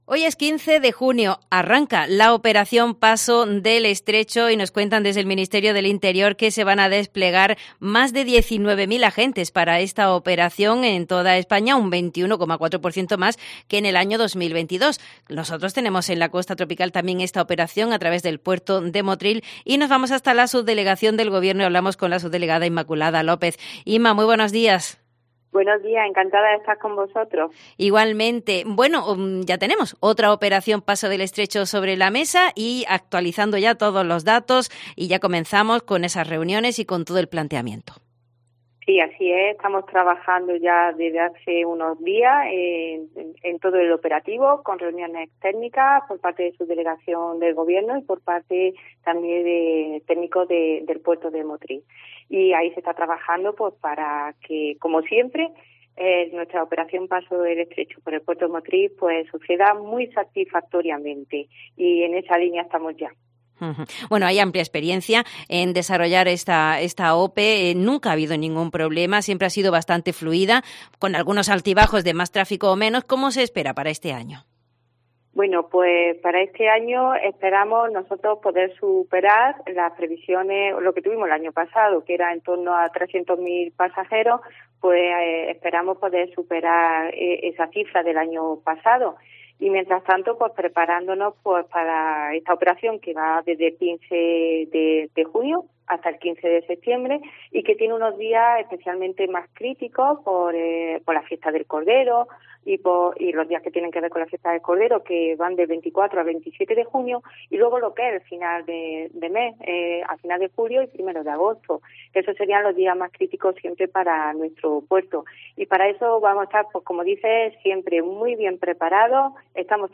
La Subdelegada del Gobierno nos explica que el próximo 23 de junio se celebra la reunión de coordinación
La Operación Paso del Estrecho comienza este jueves 15 de junio en toda España y en Motril será el 23 de junio cuando se celebre la reunión de coordinación, como nos explica en COPE la Subdelegada del Gobierno en Granada, Inmaculada López Calahorro.